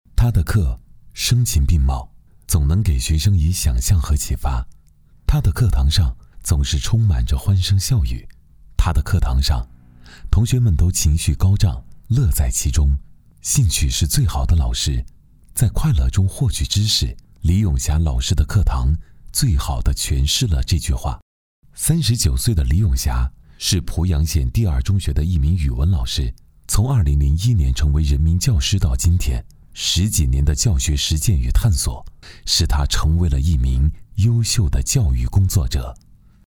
人物男125号
年轻时尚 人物专题
质感青年男音，特点，时尚年轻，大气浑厚。擅长微电影旁白，科技宣传，讲述等不同题材。